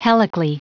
Prononciation du mot helically en anglais (fichier audio)
Prononciation du mot : helically